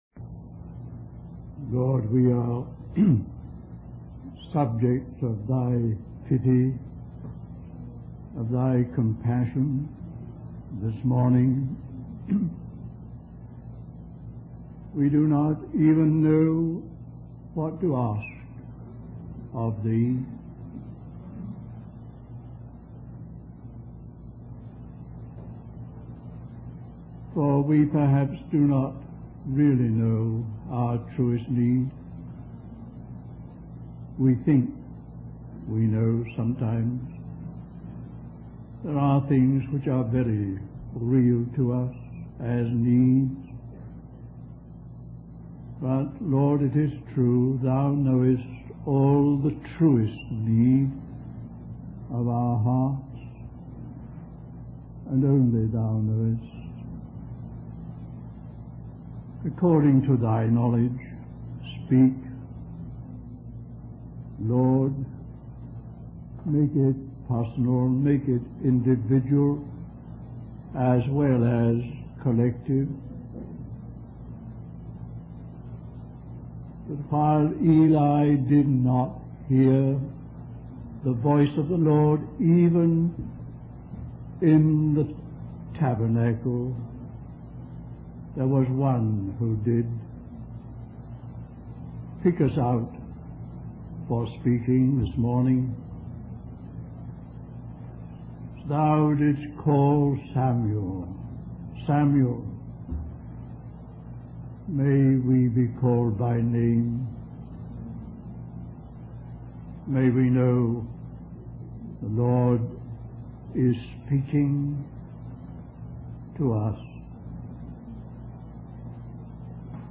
1969 Wabanna (Atlantic States Christian Convocation) Stream or download mp3 Summary The Word or logos is the thought of God.